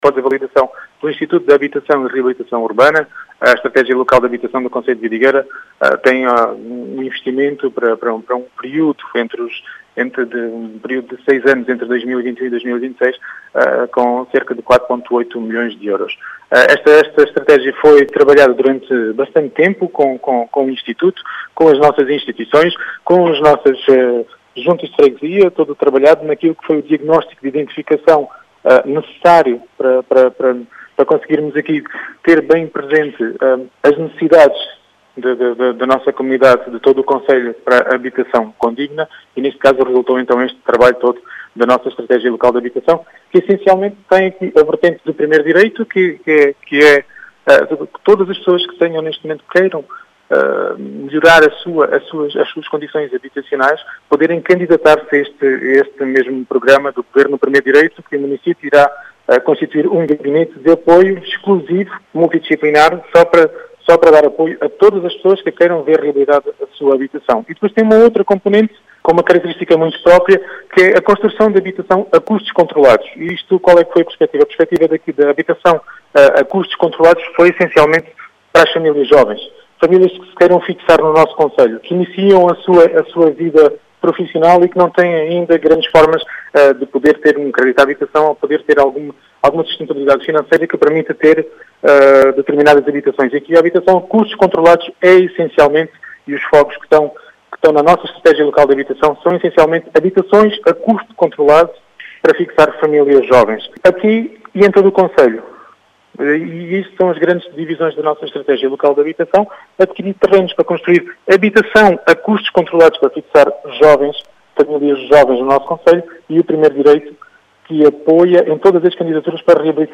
As explicações de Rui Raposo, presidente da Câmara de Vidigueira.